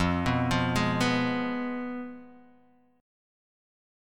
Fdim Chord